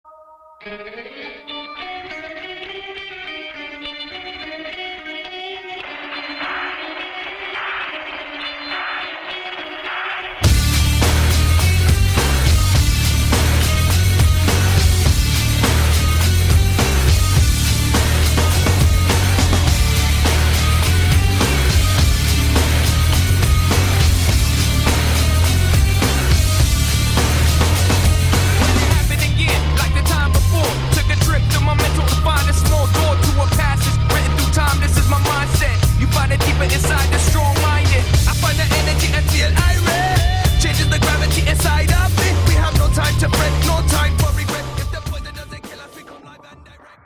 • Качество: 128, Stereo
мужской голос
громкие
hardcore
nu metal
речитатив
Rapcore